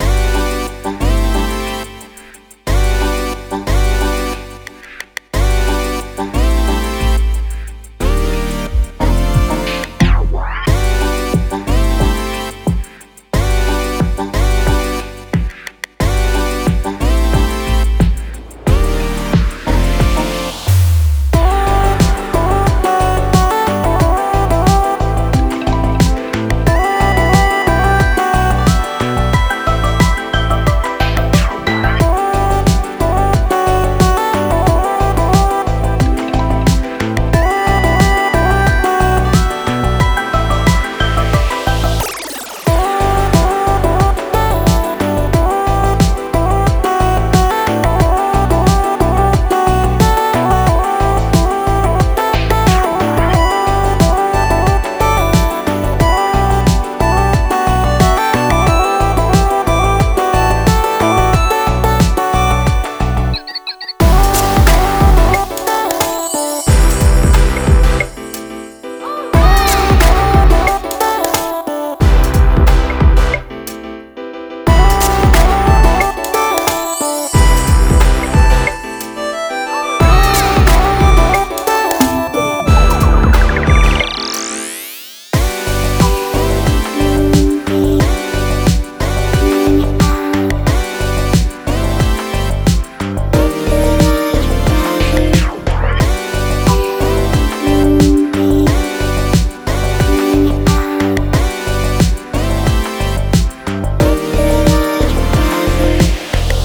◆ジャンル：Chill/チル